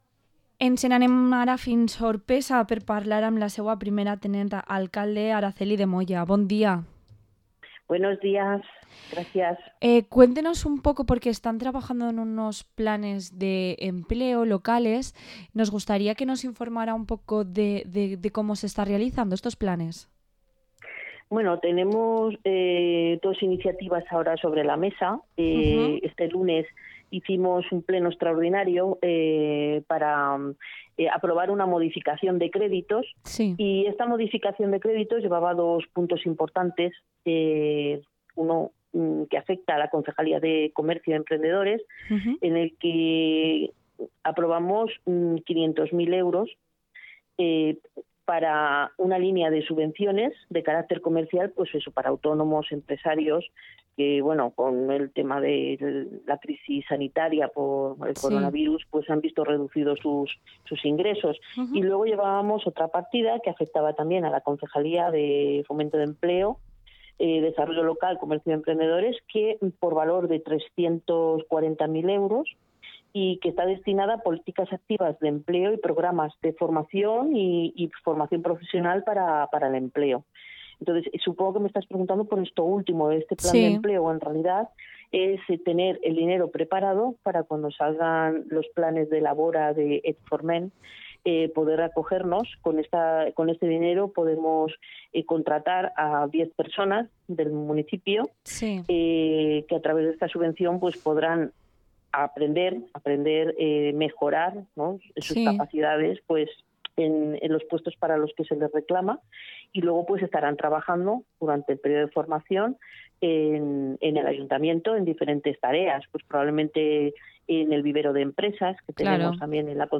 Entrevista a la primera teniente alcaldesa de Oropesa, Araceli de Moya